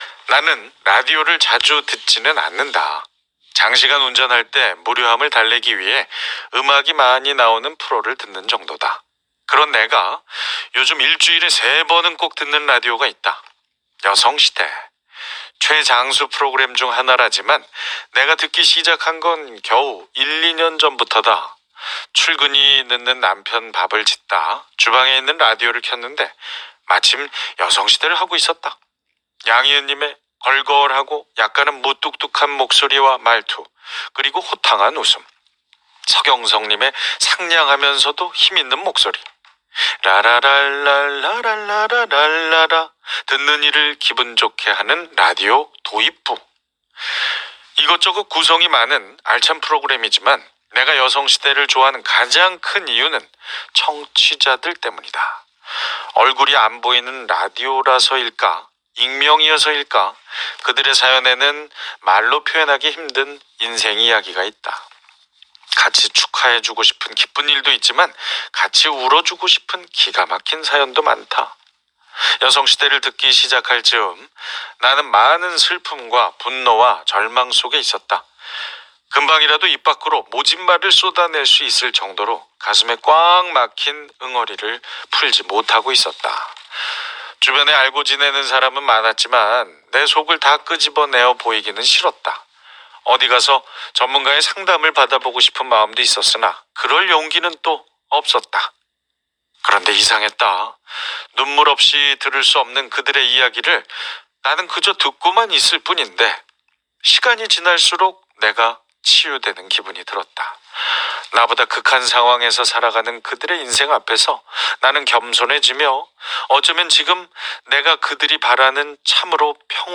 서경석 님의 안정적이고도 그윽한 목소리로 읽어 주시니 내 글이 이리 멋졌나 하는 생각이 들 정도다.
양희은 님이 프로그램을 정확하게 파악하고 있다고 칭찬해 주시니 감격스러웠다.